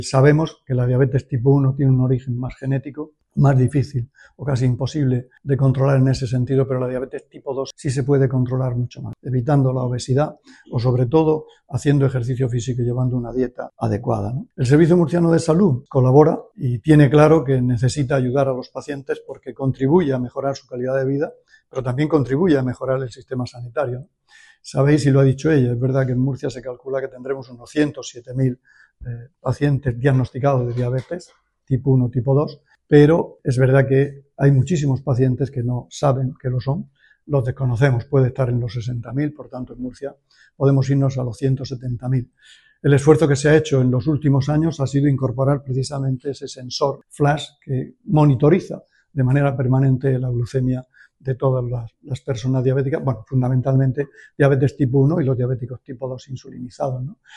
Declaraciones del consejero de Salud, Juan José Pedreño, en la presentación de los actos organizados en torno al Día Mundial de la Diabetes